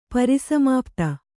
♪ pari samāpta